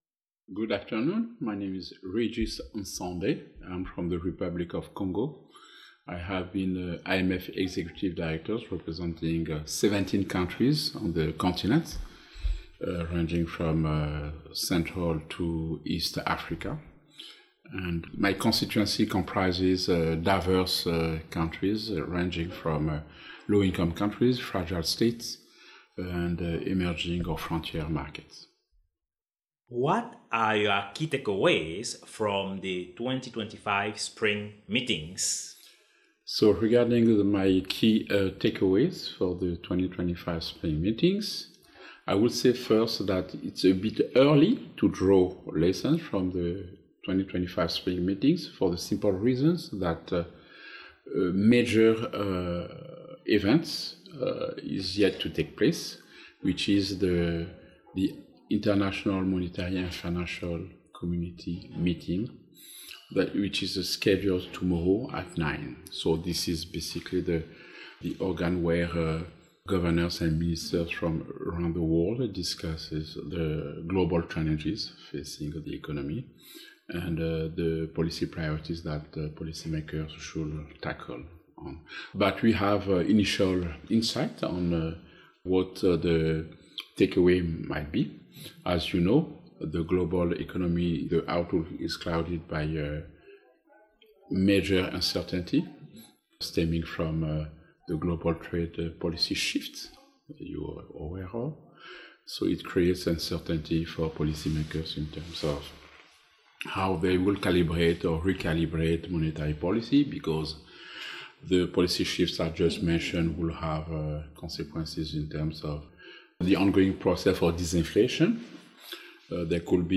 Foresight Africa podcast at the 2025 World Bank/IMF Spring Meetings
In this interview, he speaks with Regis N’Sonde, an executive director at the International Monetary Fund representing 17 sub-Saharan African countries, including all central and eastern African countries.